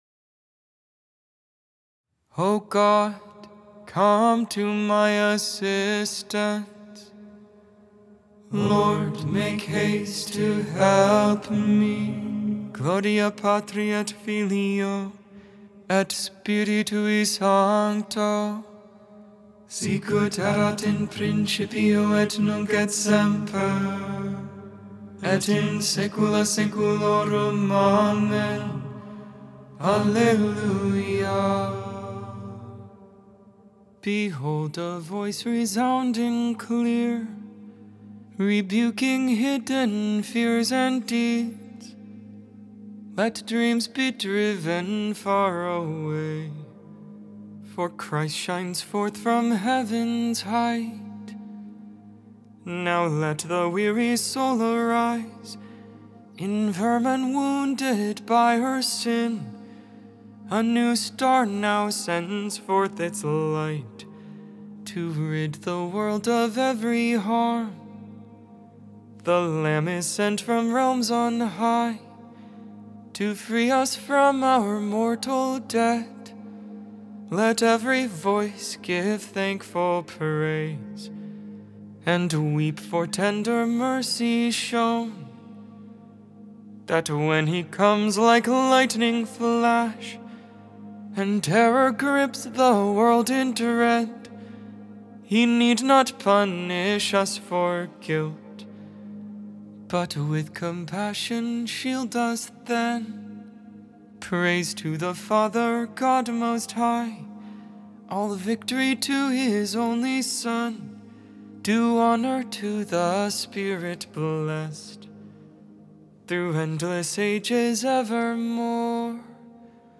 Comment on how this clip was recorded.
12.6.24 Lauds, Friday Morning Prayer